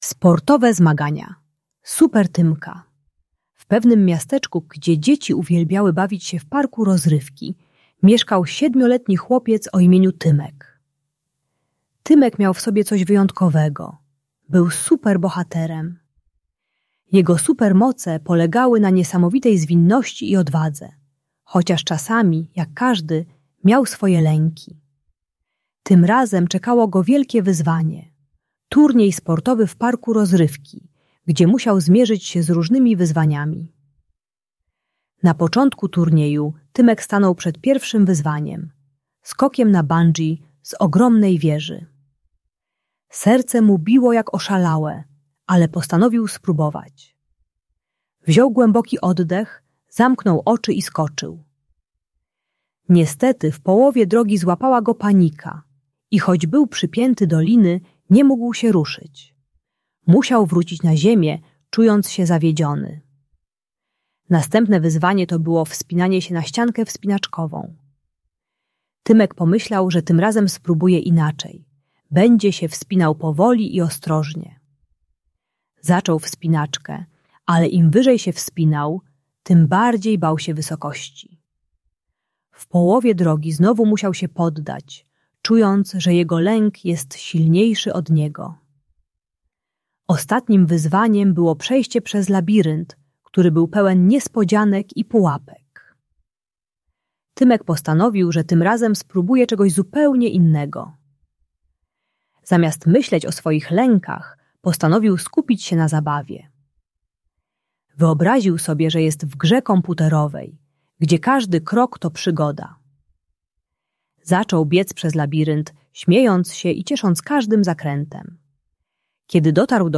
Sportowe Zmagania Super Tymka - Lęk wycofanie | Audiobajka